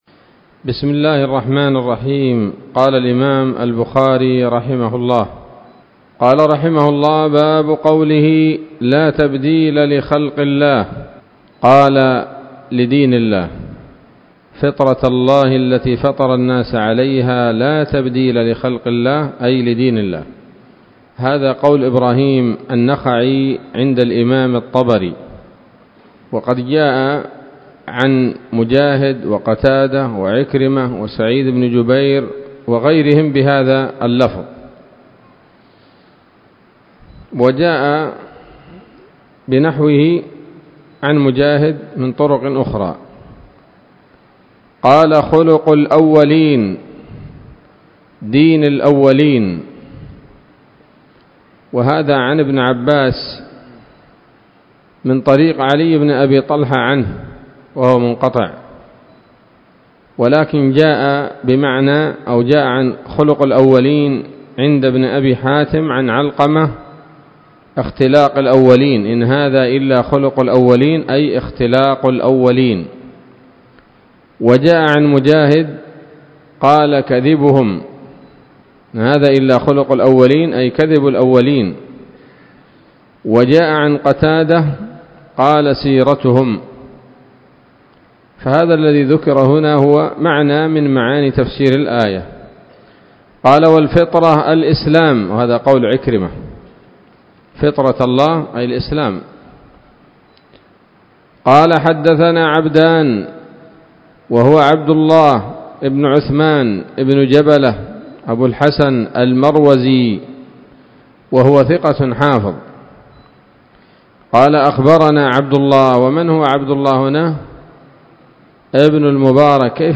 الدرس الثامن والتسعون بعد المائة من كتاب التفسير من صحيح الإمام البخاري